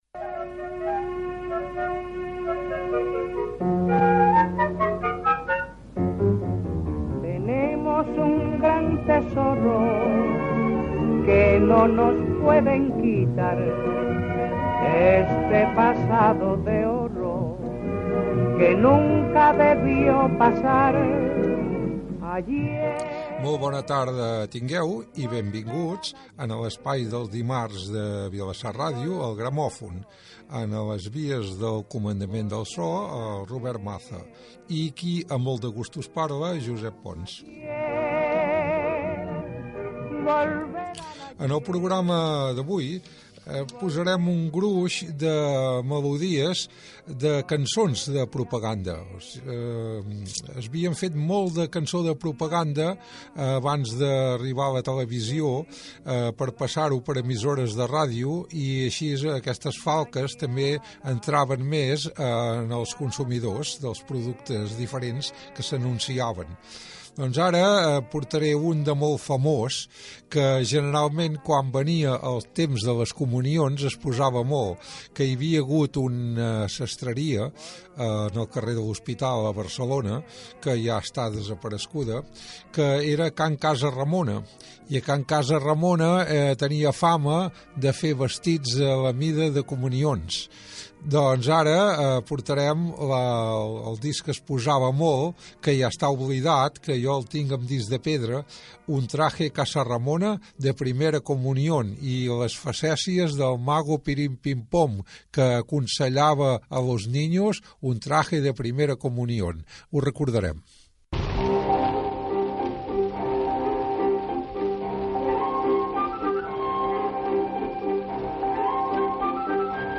Sintonia, presentació i resproduccions de publicitat radiofònica antiga